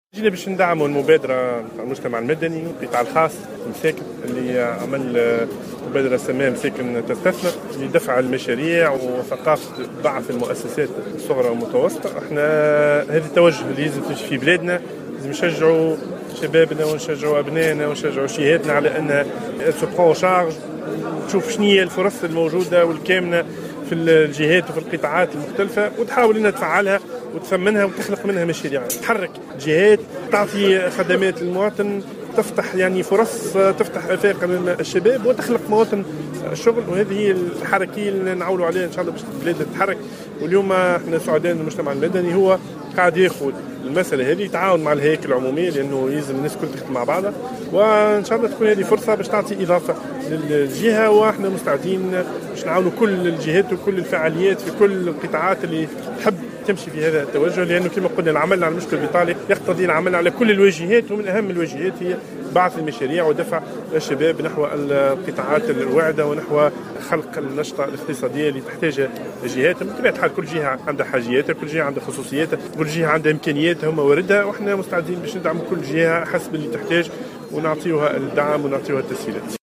وقال العذاري في تصريح للجوهرة أف أم خلال افتتاحه اليوم الاثنين 11 أفريل 2016 يوما إعلاميا بخصوص دفع الاستثمار وبعث المشاريع بمساكن من ولاية سوسة إنه يدعم مبادرة "مساكن تستثمر" التي قام بها المجتمع المدني بالتعاون مع الهياكل العمومية والتي تهدف إلى بعث المؤسسات الصغرى والمتوسطة في الجهة.